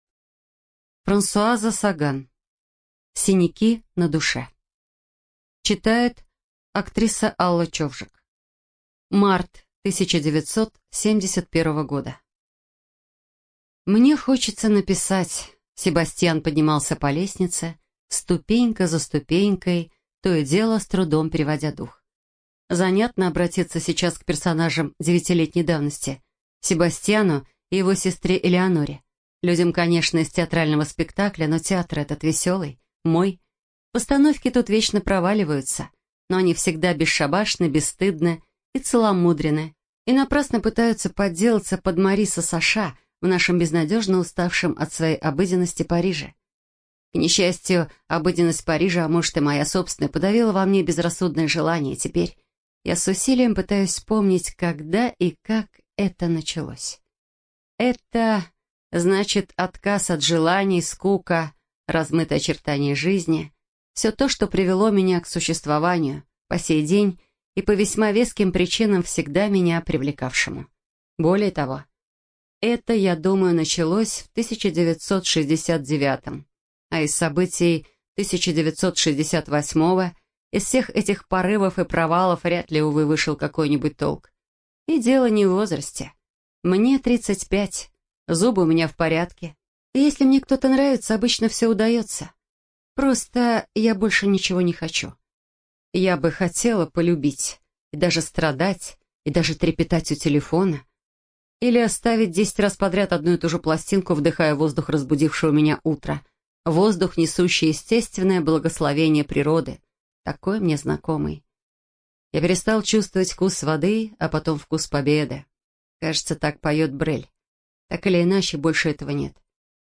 Эту и другие книги нашей библиотеки можно прослушать без использования компьютера с помощью Android-приложения или тифлофлешплеера с поддержкой онлайн-доступа.